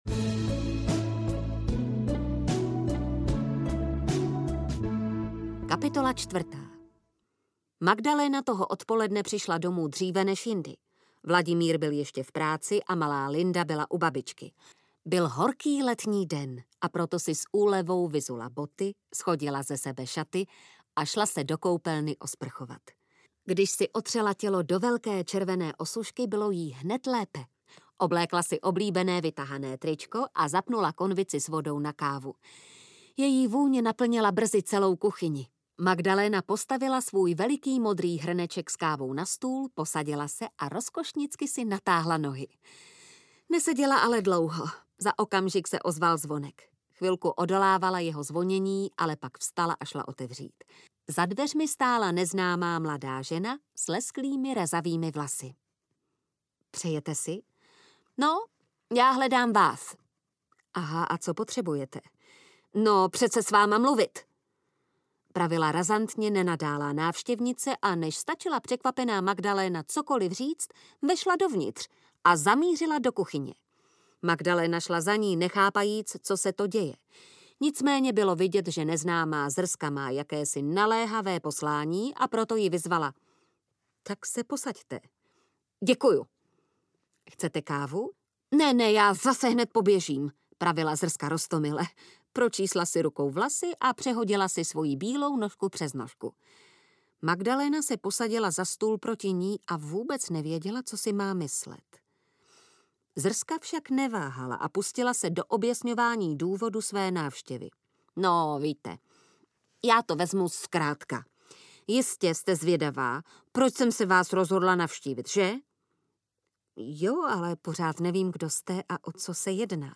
Sexy hrátky audiokniha
Ukázka z knihy